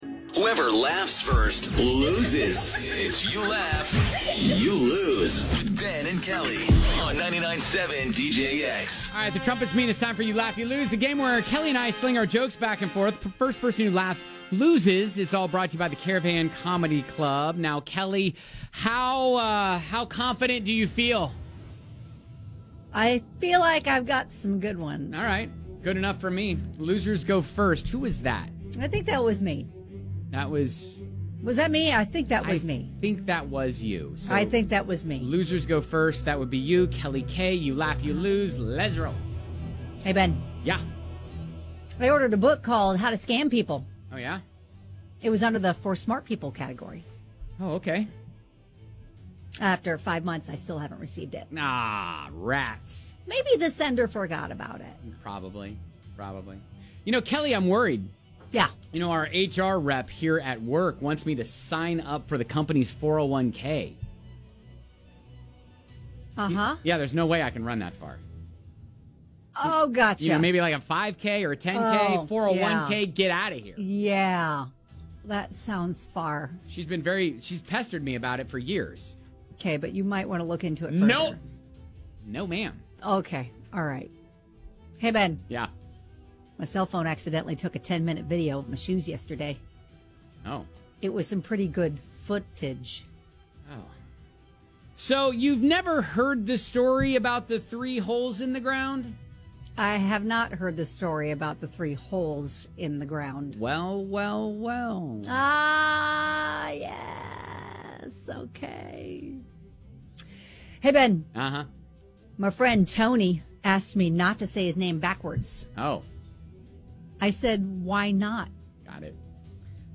toss jokes back and forth until someone laughs